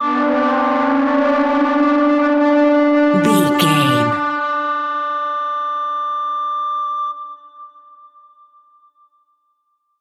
Screaming Witch Stinger.
Sound Effects
Atonal
scary
ominous
dark
eerie
synth
ambience
pads